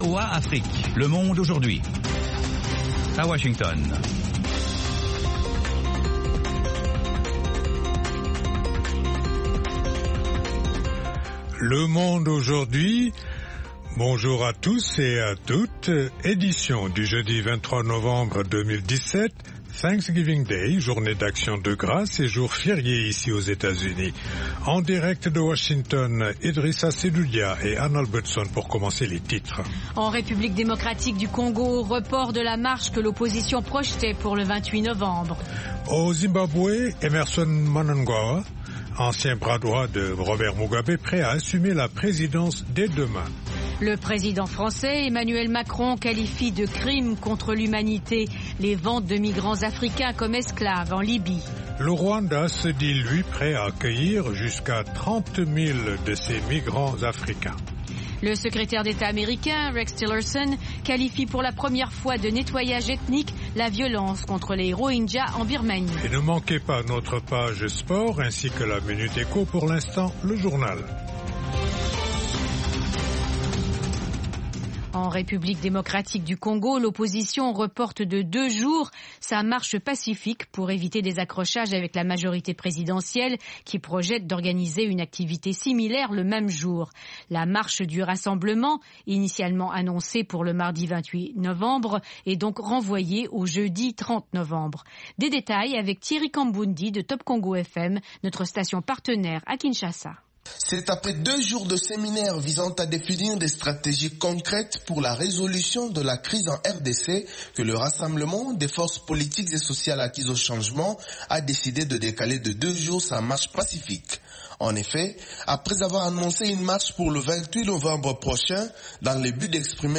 Le Monde aujourd'hui, édition pour l'Afrique de l'Ouest, une information de proximité pour mieux aborder les préoccupations de nos auditeurs en Afrique de l’Ouest. Toute l’actualité sous-régionale sous la forme de reportages et d’interviews.